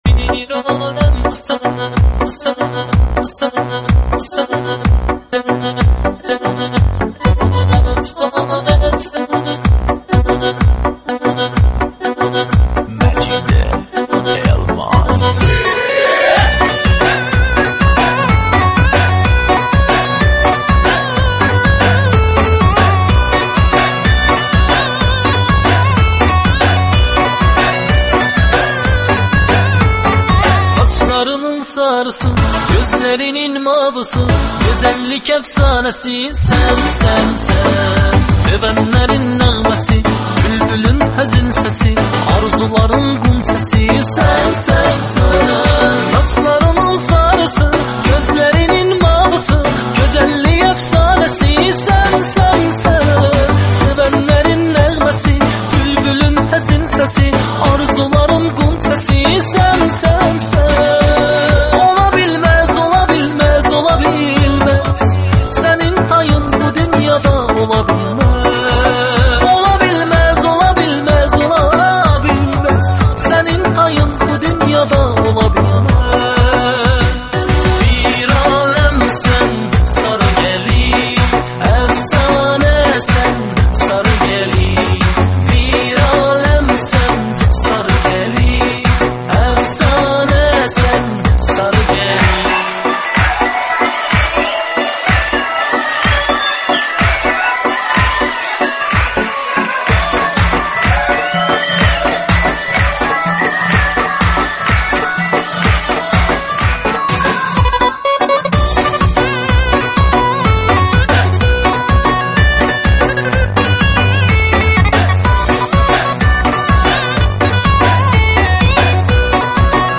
آهنگ شاد آذری برای رقص